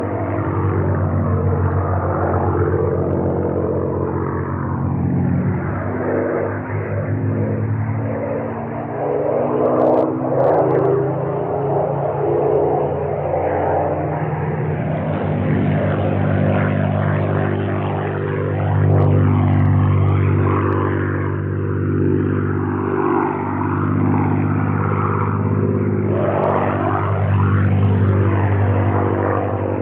dist.wav